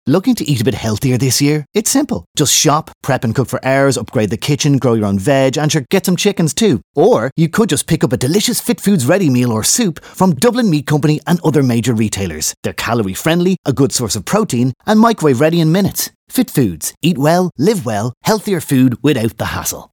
Fit-Foods-Dublin-20sec-Radio-V1.mp3